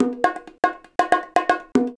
130BONGO 14.wav